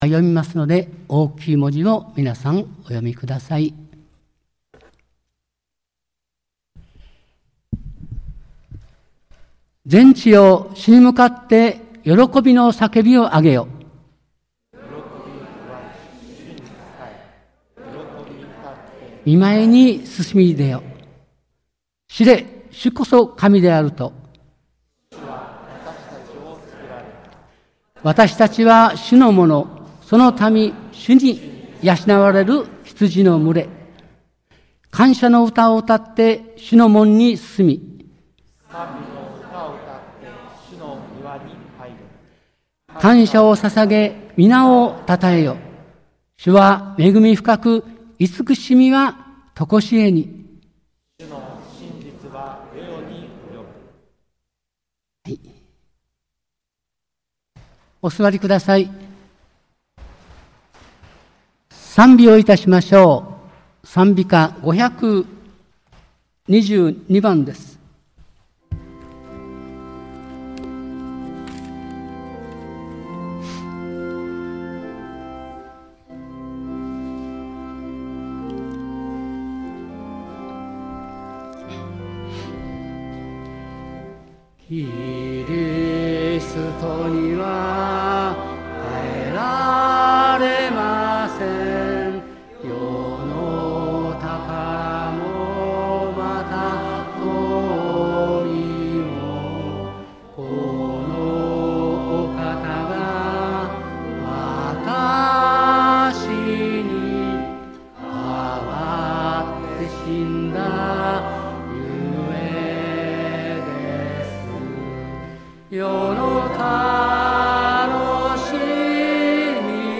日曜礼拝（音声）